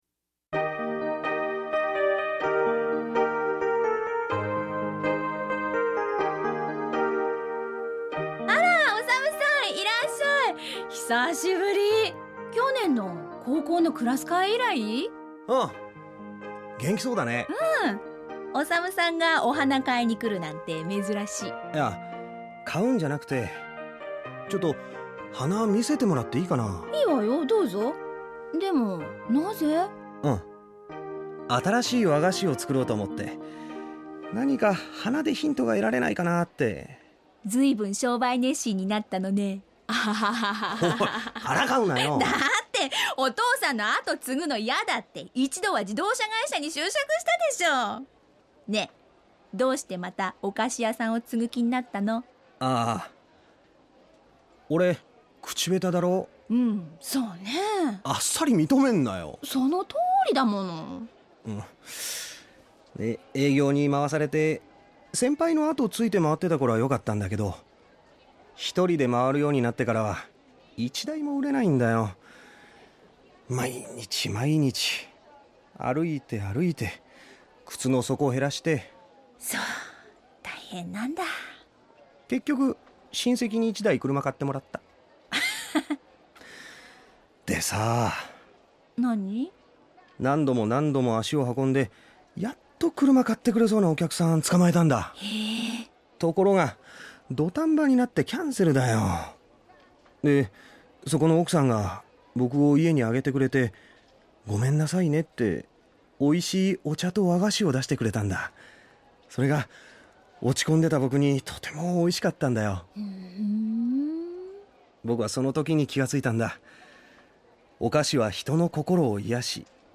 ●ラジオドラマ「花ものがたり」